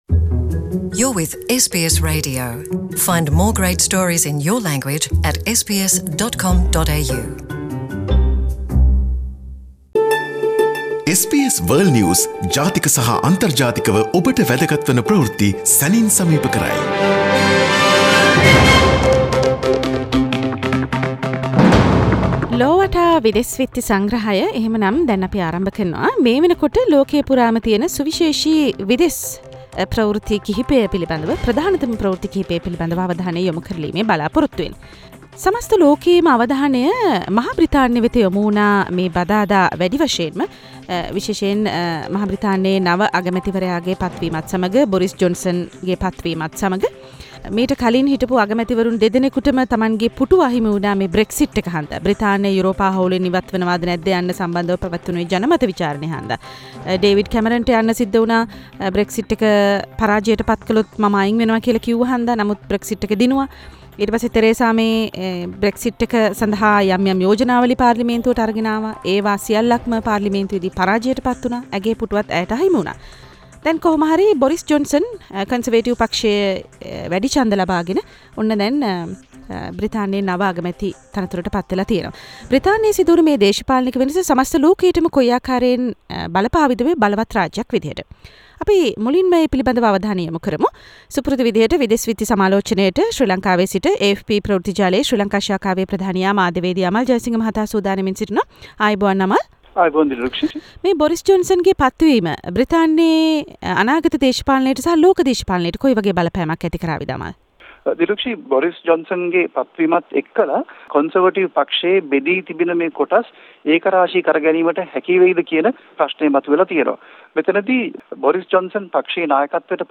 විදෙස් විත්ති සමාලෝචනය